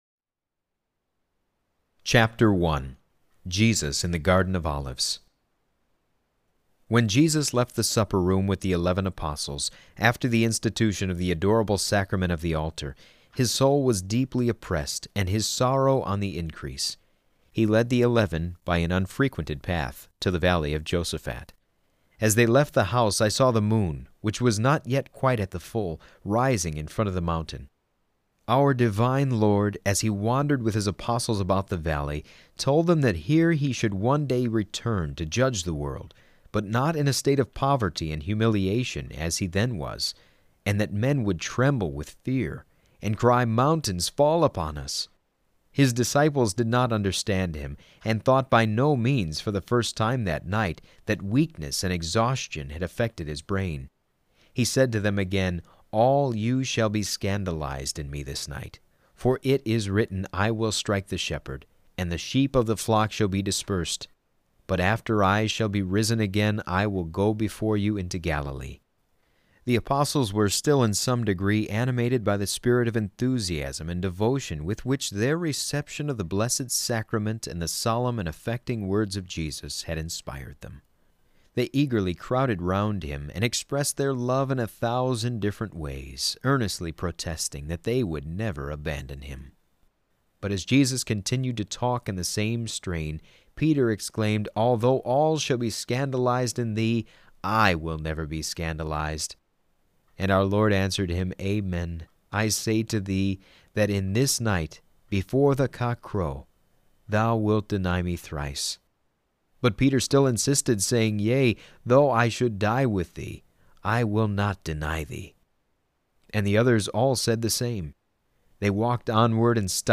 I used a mid-Western Protestant in his 30's only requiring that he read every word as written.
I still prefer having a male reader for this particular work.
Well, what you provided definitely sounds wholly professional in every regard.